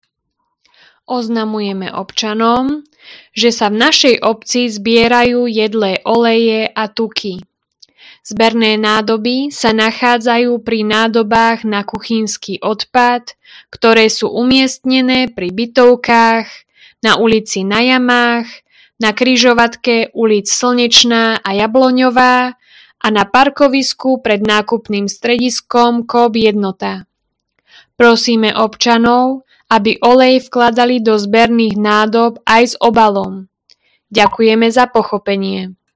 Hlásenie obecného rozhlasu – zber jedlých olejov a tukov